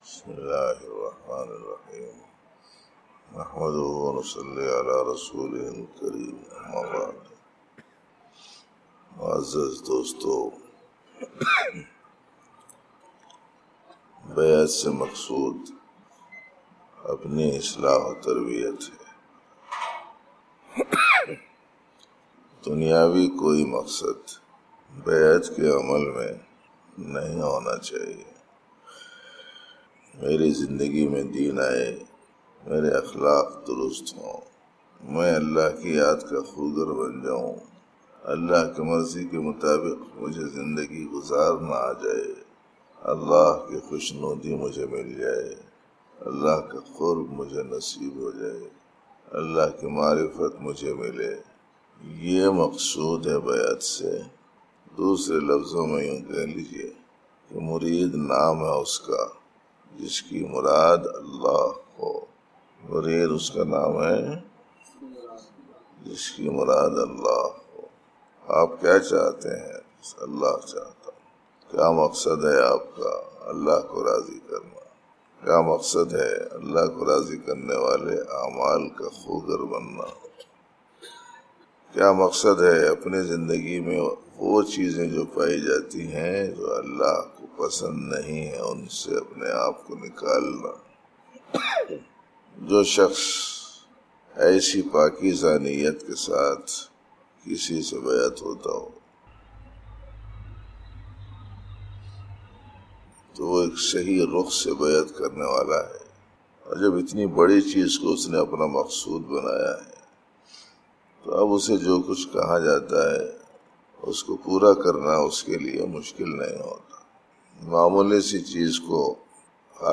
Dars e Tasawwuf